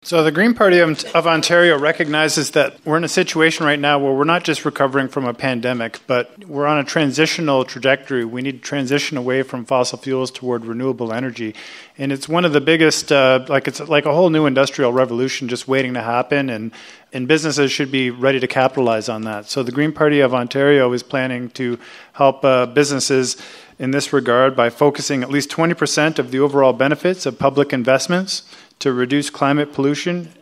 The Haldimand-Norfolk candidates spoke on the topic at the Royal Canadian Legion in Simcoe on Thursday night.